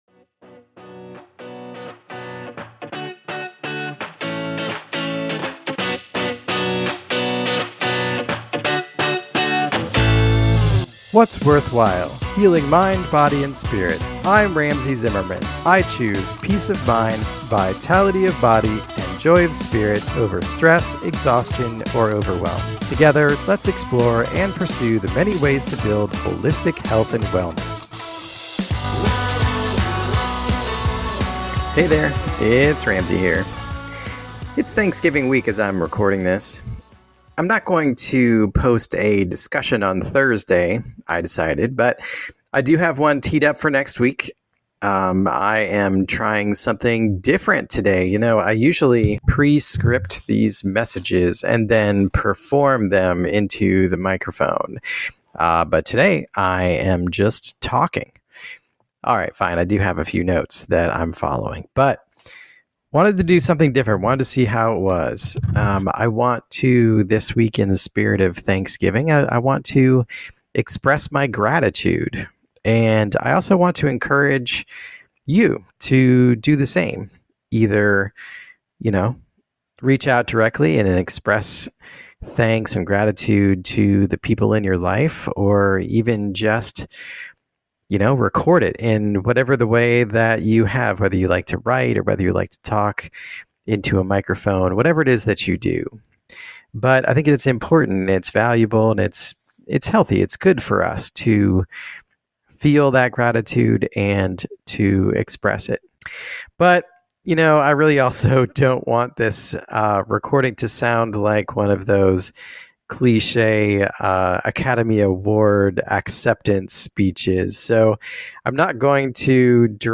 So I tried something different this week, I spoke off the cuff, with just a few notes instead of a prepared message.